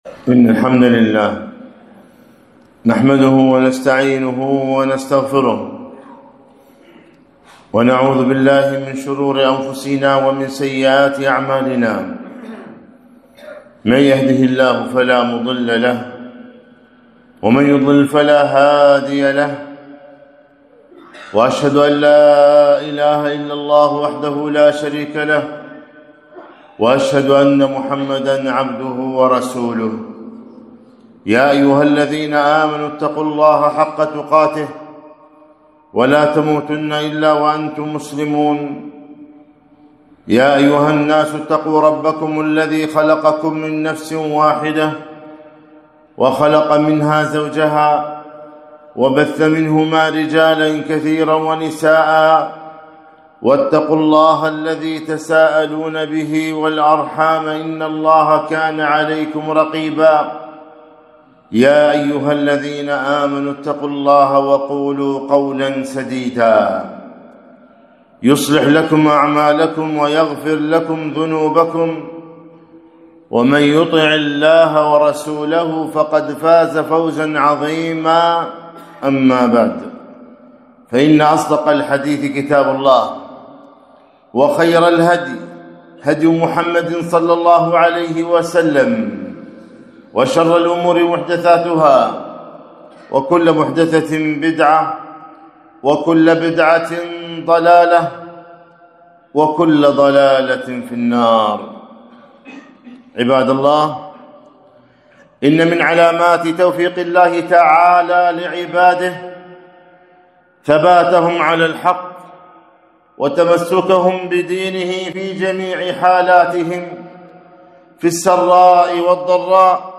خطبة - فالله خير حافظا وهو أرحم الراحمين